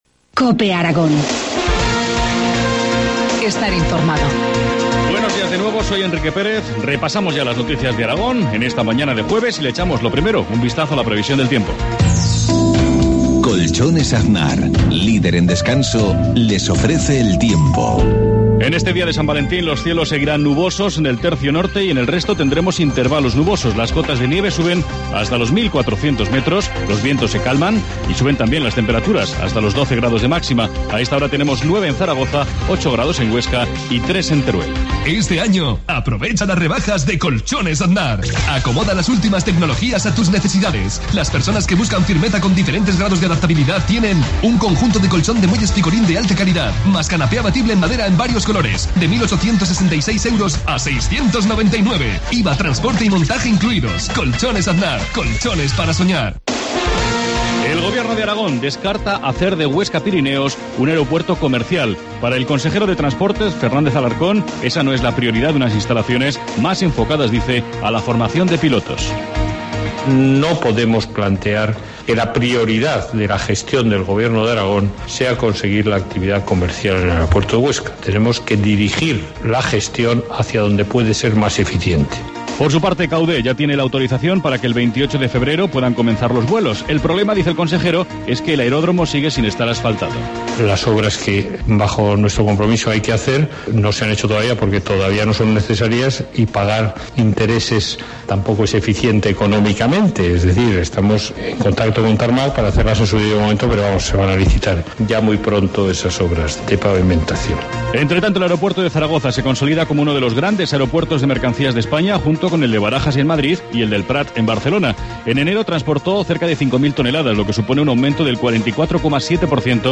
Informativo matinal, jueves 14 de febrero, 7.53 horas